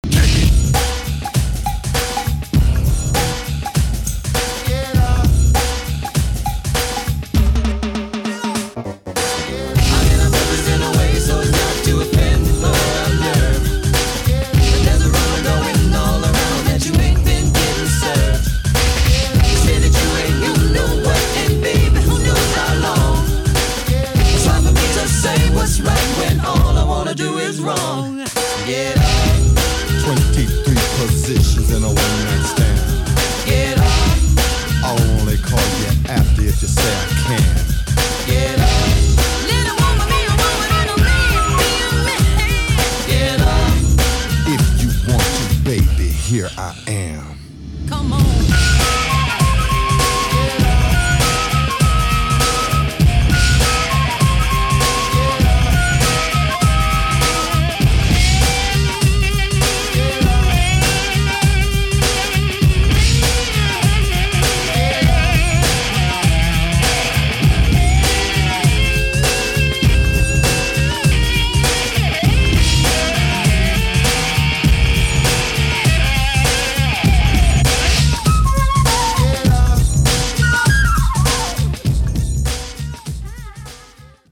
• Качество: 320, Stereo
dance